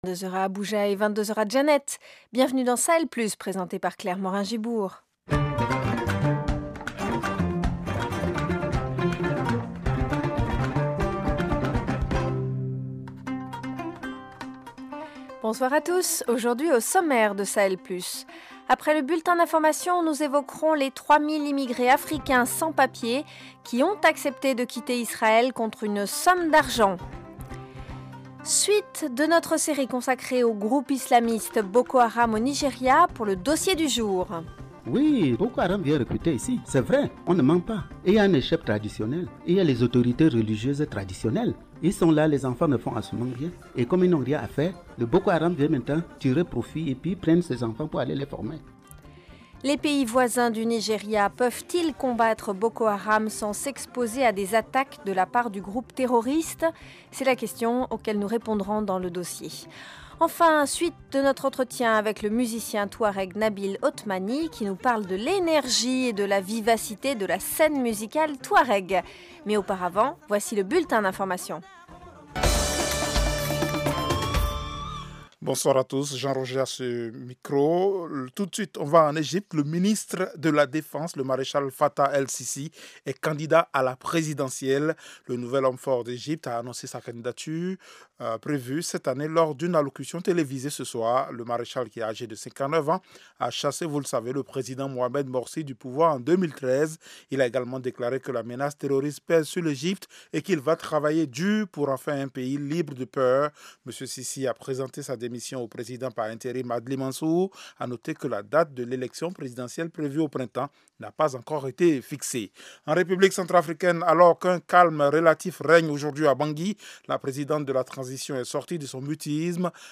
Au programme : 3000 immigrés africains sans papiers ont accepté de quitter Israël contre une somme d’argent, certaines ONG évoquent des pressions. Dossier : les pays voisins du Nigéria peuvent-ils combattre Boko Haram sans s’exposer à des attaques de la part du groupe terroriste ? Page culture : suite de notre entretien avec le musicien touareg